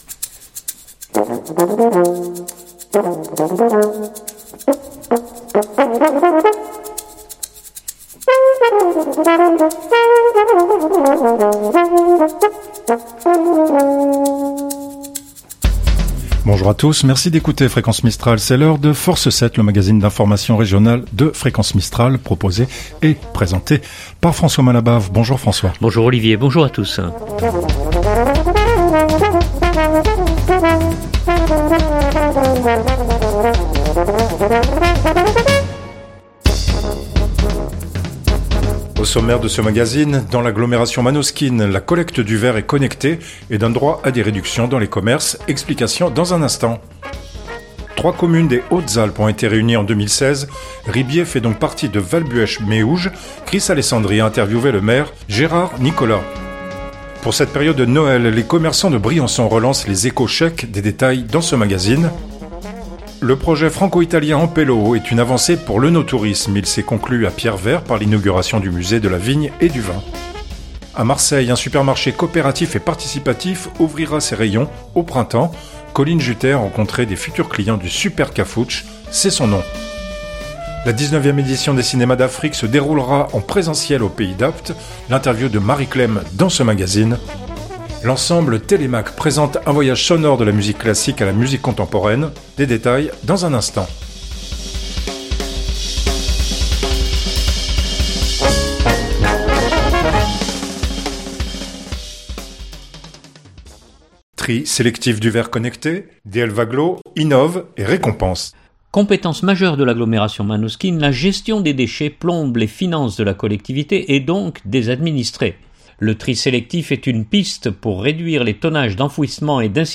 un magazine d’information régional